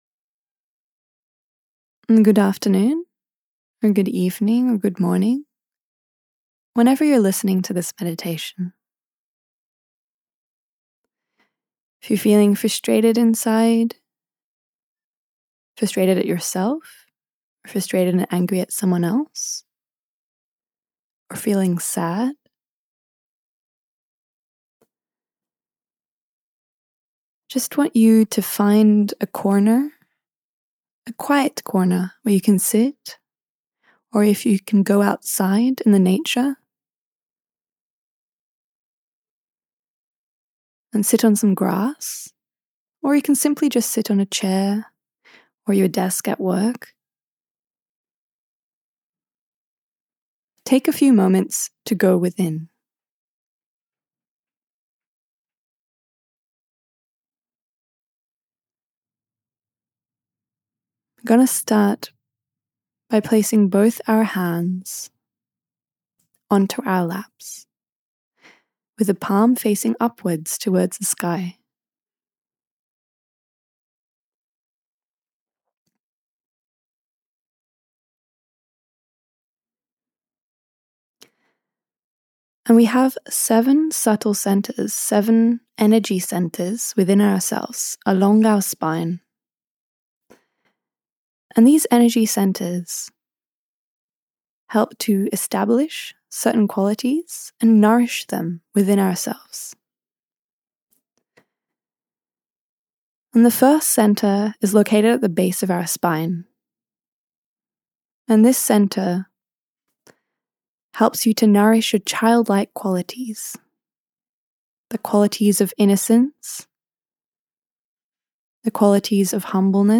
MEDITATION Shri Mataji Nirmala Devi founder 0:00 Delving Deeper All pervading power